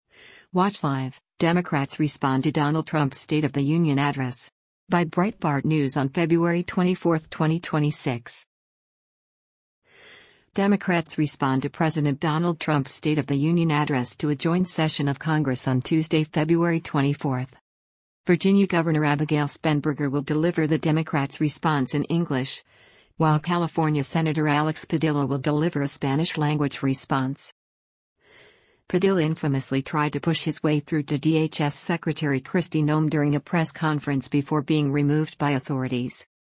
Virginia Governor Abigail Spanberger will deliver the Democrats’ response in English, while California Senator Alex Padilla will deliver a Spanish-language response.